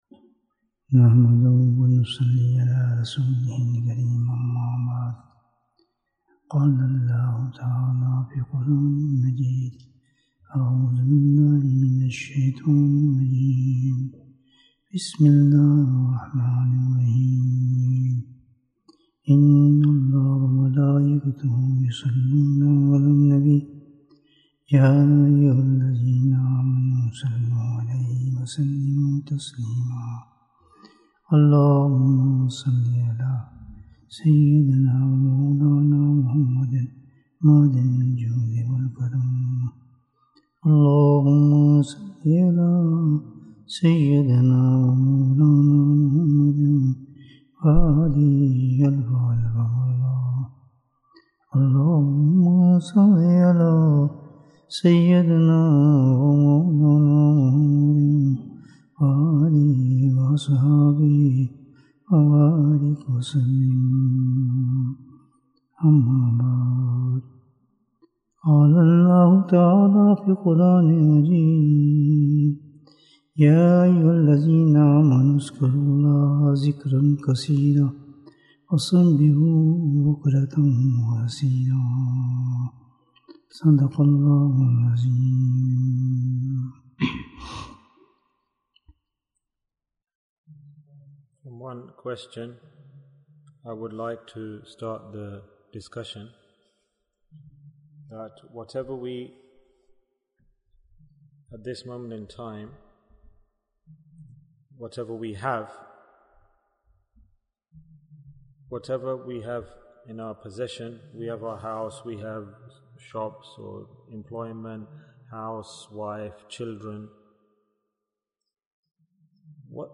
Bayans Clips Naat Sheets Store Live Why is Dhikrullah Important? Bayan, 46 minutes 29th September, 2022 Click for Urdu Download Audio Comments SubhanAllah what a unique explanation.